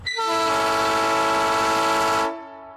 Train Horn 3 Sound Effect Free Download
Train Horn 3